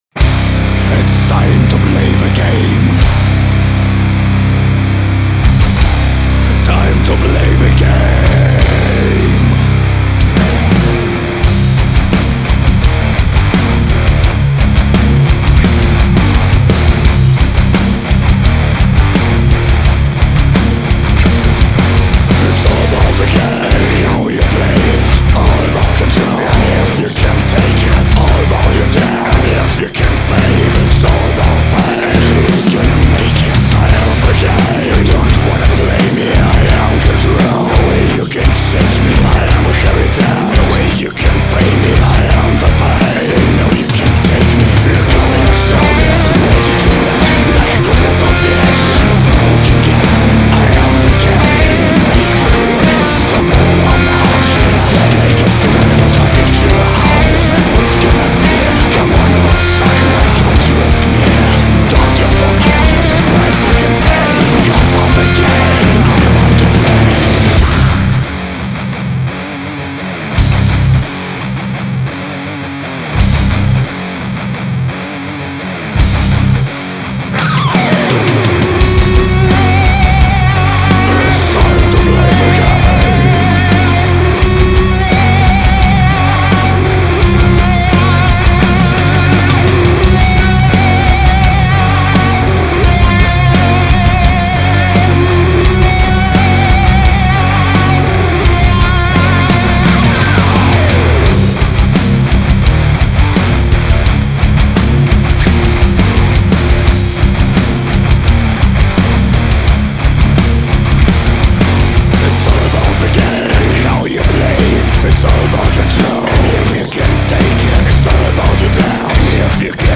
отаке басы :rolleyes:
только что свёл, ещё тёплый!
Хорошо звучит, но я бы барабанам дал бы децебельчик добавил. Ну и сменил бы бочку, тембр мне её не вкатывает.